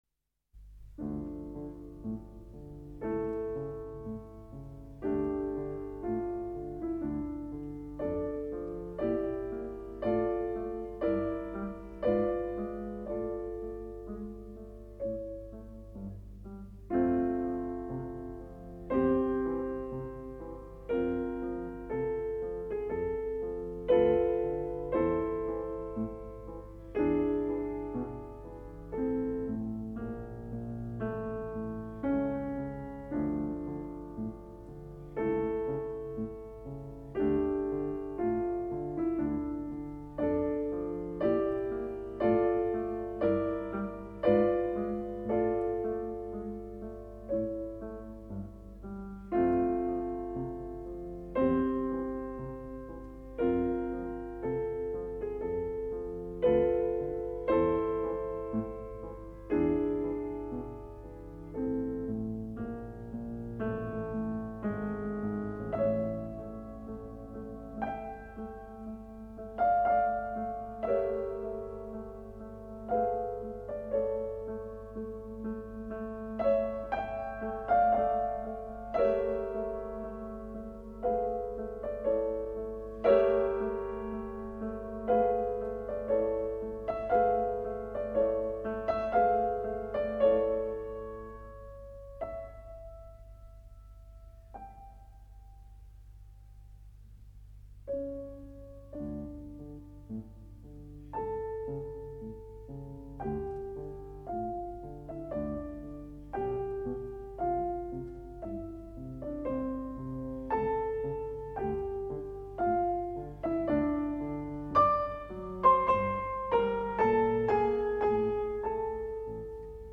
Maria Grinberg, piano – 1964 *****
Cette très belle sonate pour piano, composée en 1801, est d’un caractère paisible et champêtre tout-à-fait remarquable : c’est vraiment du meilleur Beethoven !
J’aime beaucoup cette version de Maria Grinberg, qui enregistra durant les années 60 –prises de son soviétiques malheureusement assez précaires…– une belle intégrale des sonates pour piano de Beethoven, longtemps indisponible en Occident.
Dans cette sonate, Maria Grinberg est l’une des rares pianistes à ne pas précipiter le deuxième mouvement, dont le rythme d’une promenade au pas tranquille est souvent abordé trop rapidement à mes oreilles -cf. extrait-.